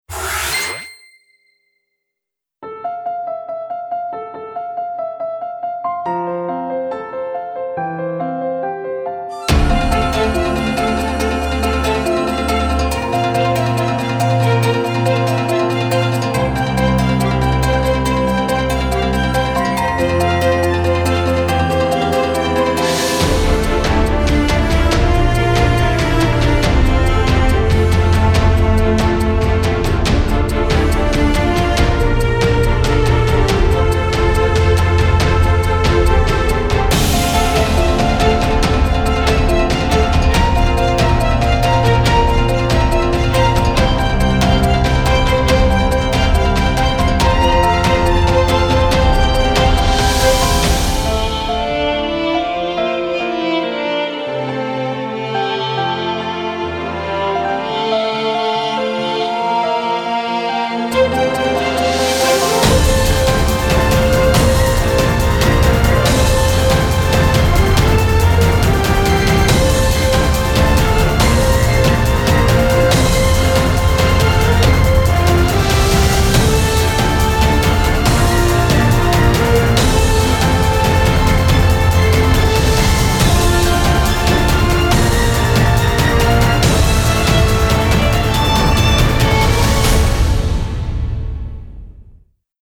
to show quality of orchestral sounds